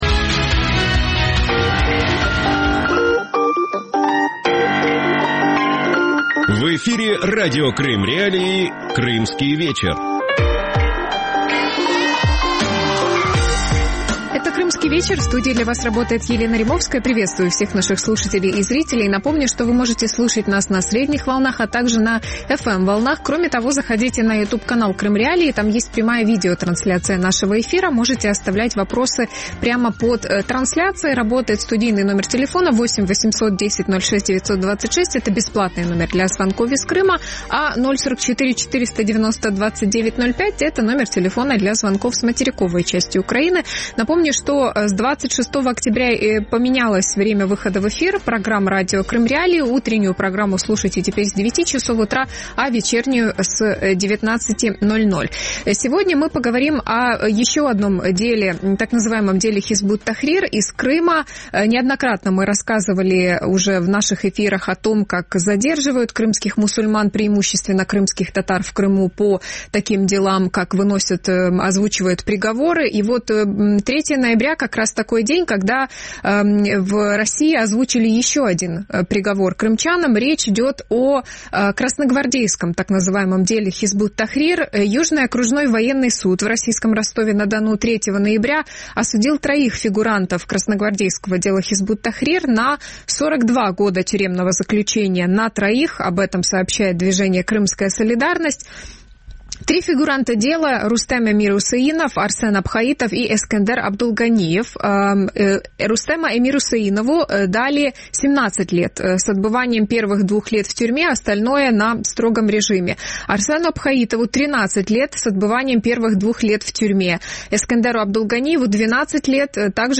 И что означает ночное задержание на мосту через Керченский пролив? Эти и другие актуальные темы в студии Радио Крым.Реалии в ток-шоу